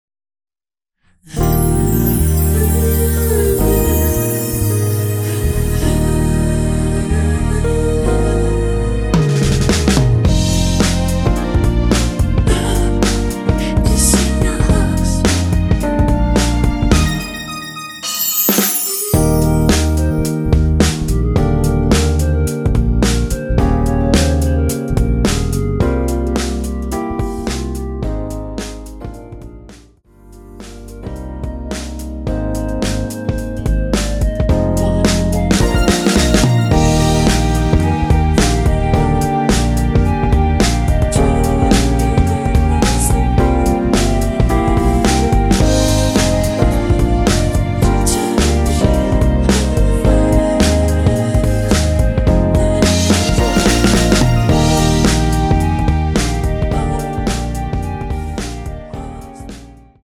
원키에서(-2)내린 멜로디와 코러스 포함된 MR입니다.(미리듣기 확인)
Eb
앞부분30초, 뒷부분30초씩 편집해서 올려 드리고 있습니다.
중간에 음이 끈어지고 다시 나오는 이유는